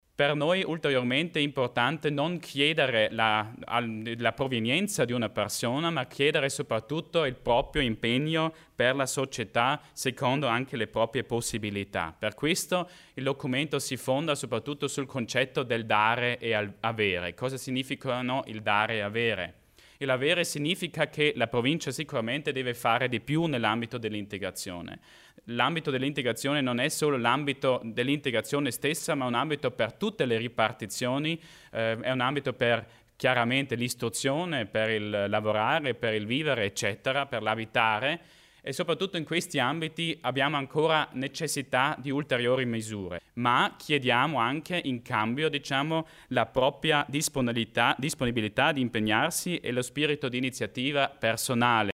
L'Assessore Achammer spiega la nuova politica di integrazione